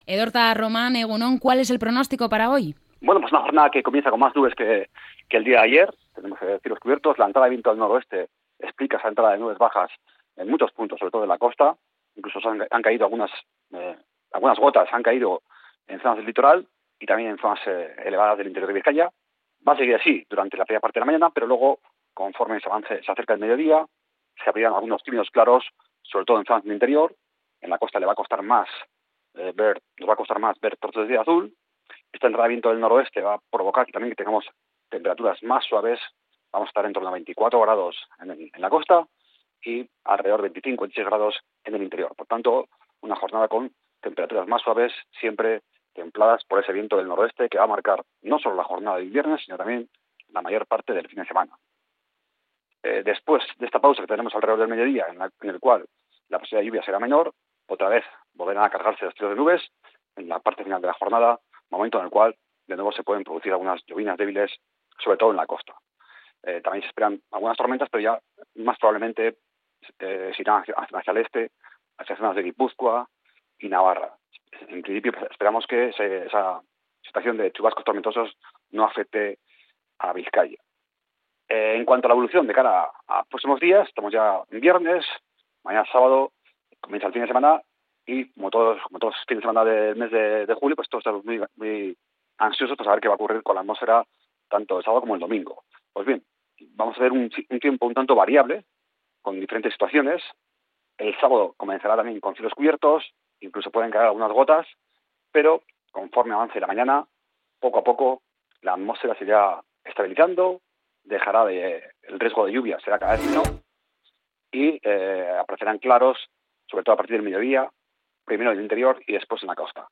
El pronóstico del tiempo para este 18 de julio